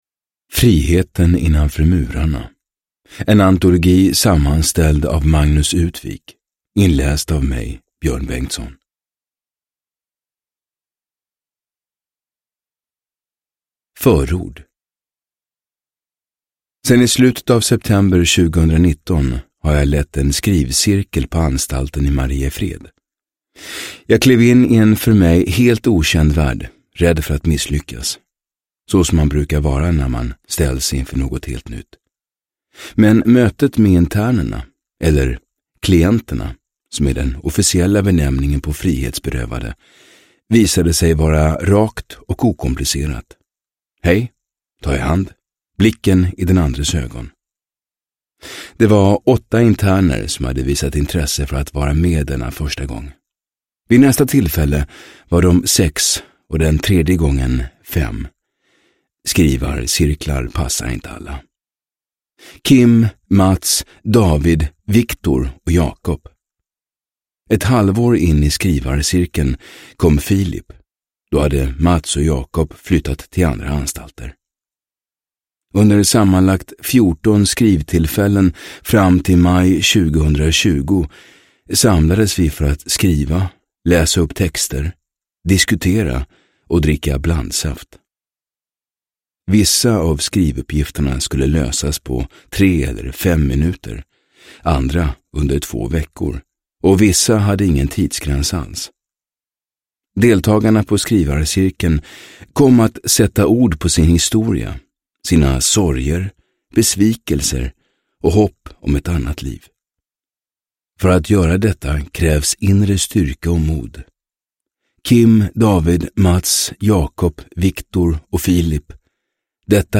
Friheten innanför murarna – Ljudbok – Laddas ner